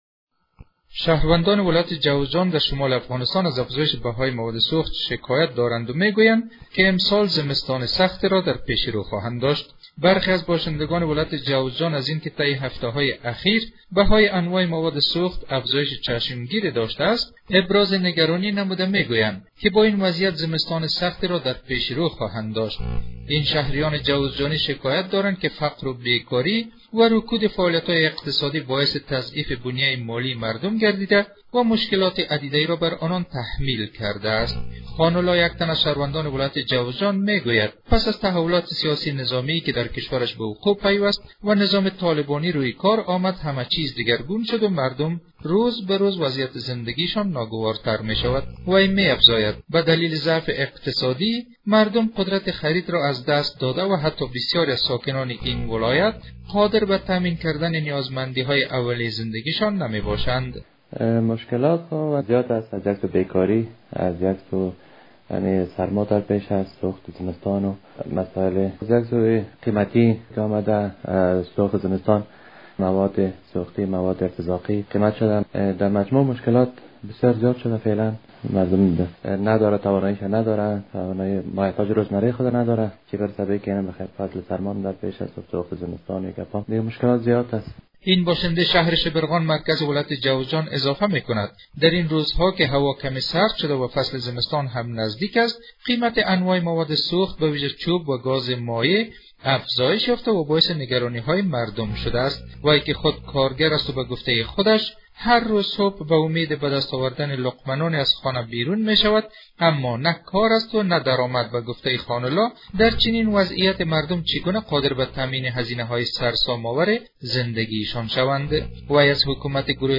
جزئیات بیشتر این خبر در گزارش تکمیلی